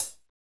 HAT A C CH0D.wav